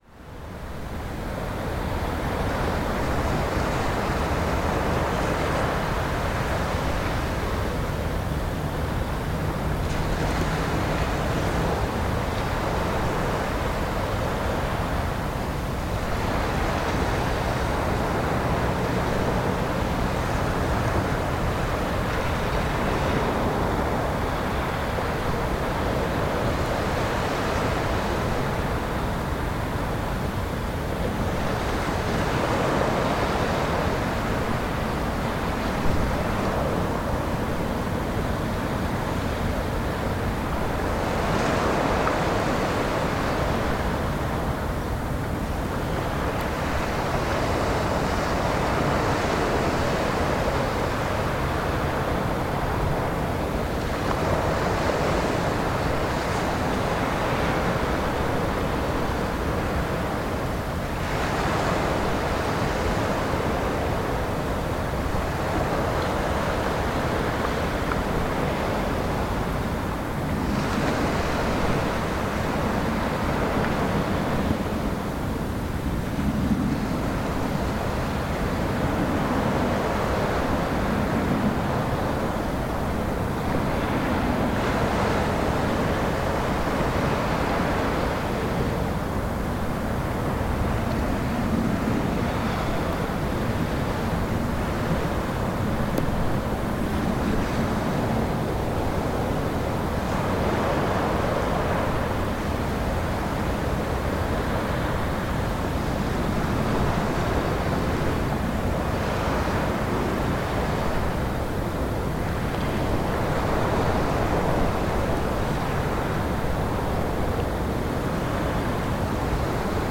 Sounds from a cliff edge at Foreness in Margate.
Part of the Cities and Memory Margate sound map for Dreamland.